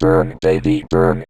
VVE1 Vocoder Phrases 05.wav